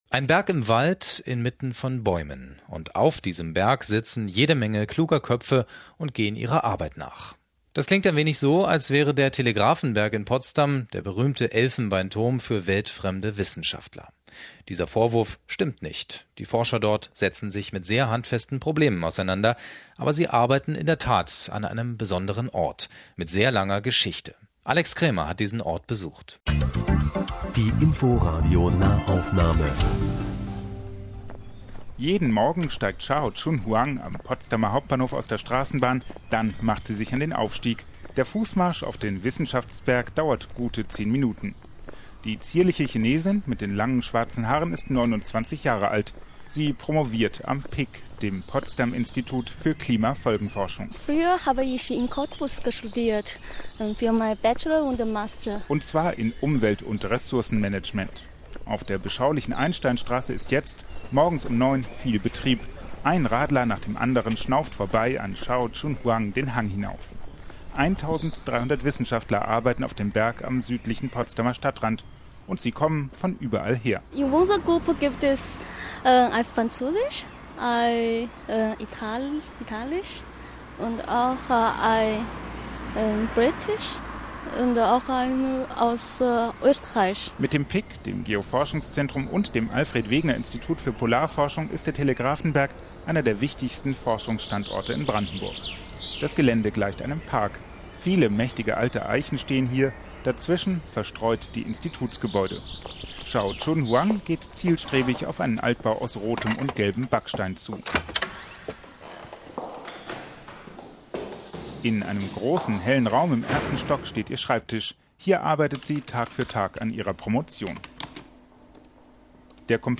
Potsdams weltbekannter Wissenschaftshügel und das PIK werden in einer Reportage des Inforadios vorgestellt.